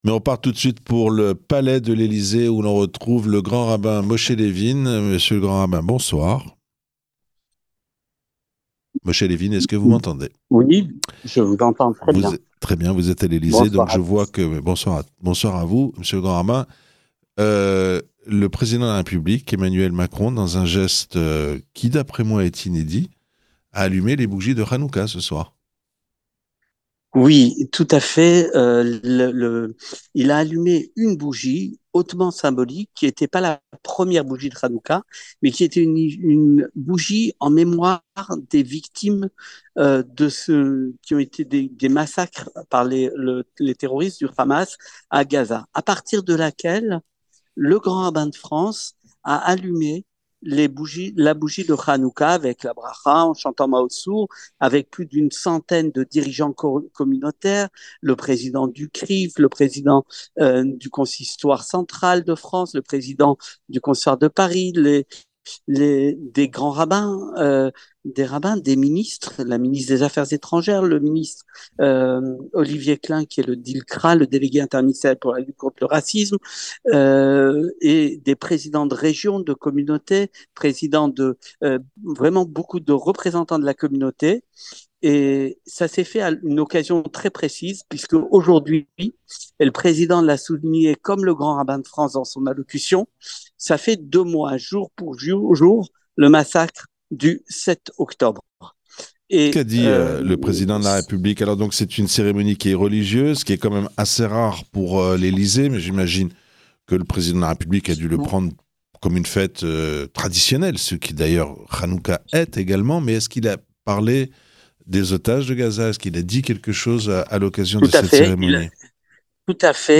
Cérémonie d'allumage des bougies de Hanoucca à l'Elysée, en présence d'Emmanuel Macron